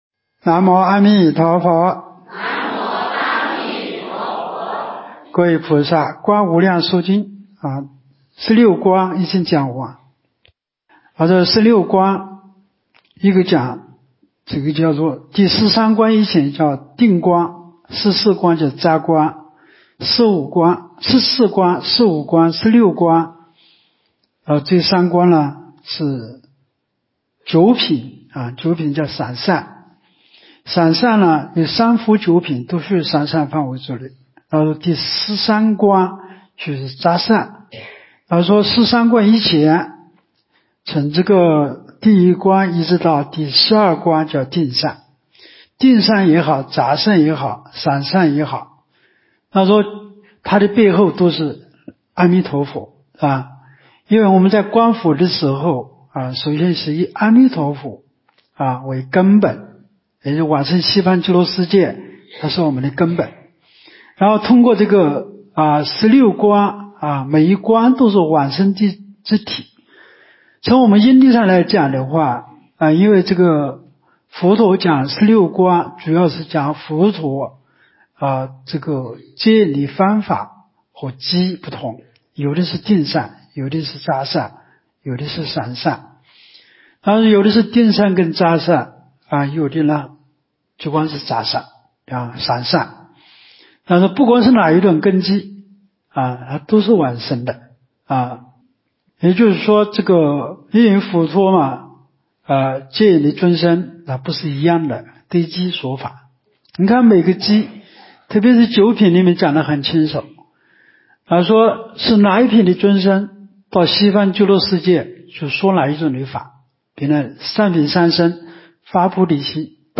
无量寿寺冬季极乐法会精进佛七开示（37）（观无量寿佛经）...